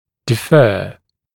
[dɪ’fɜː][ди’фё:]задерживать, откладывать, отсрочивать, оттягивать